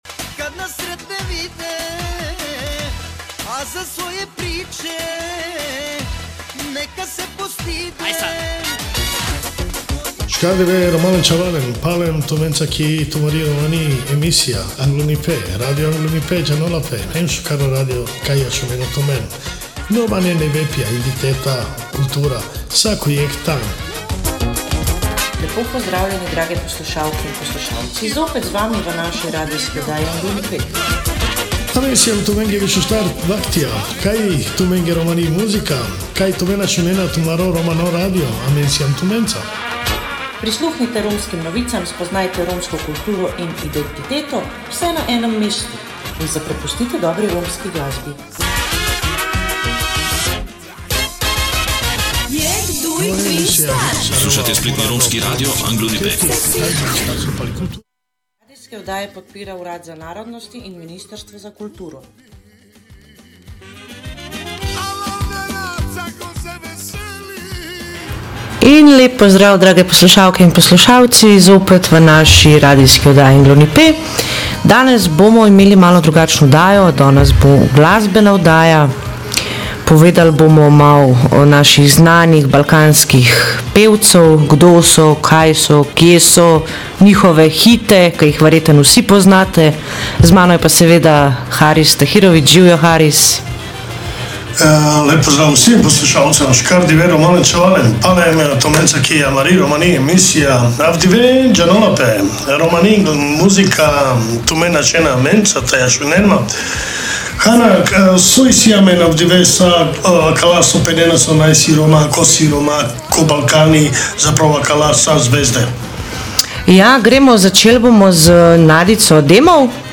kot že v naših glasbenih oddajah, vam tudi tokrat odkrivamo romske zvezde – tako tiste, ki jih že poznate, kot tudi tiste, ki še čakajo, da zasijejo. Med njimi so izjemni pevci, glasbeniki in virtuozni umetniki, ki s svojo glasbo ohranjajo bogato romsko tradicijo.
Ne zamudite naše oddaje – kjer romska glasba zasije v vsej svoji lepoti!